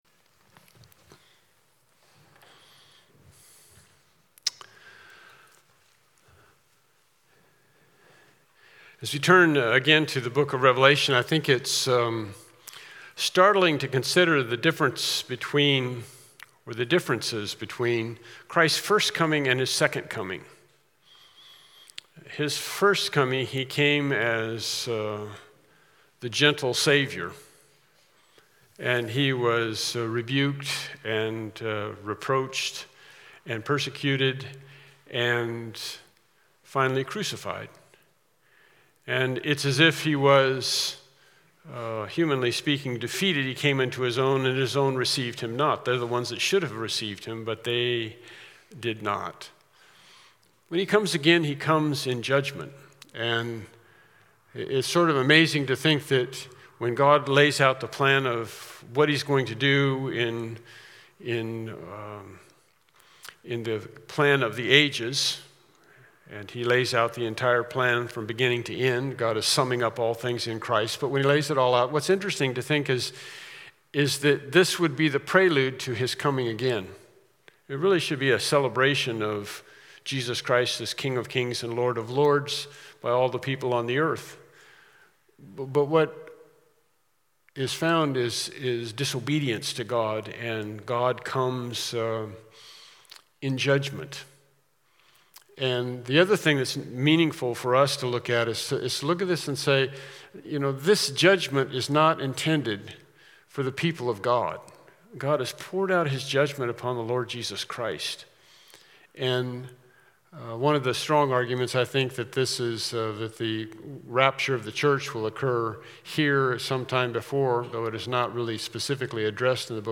Revelation 8-11 Service Type: Evening Worship Service « “All Hope in God